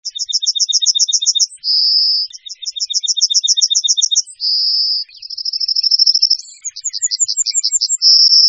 En cliquant ici vous entendrez le chant du Bruant jaune